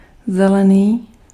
Ääntäminen
Ääntäminen : IPA: [zɛlɛniː] Tuntematon aksentti: IPA: /ˈzɛlɛˌniː/ Haettu sana löytyi näillä lähdekielillä: tšekki Käännös 1. verde {m} Suku: f .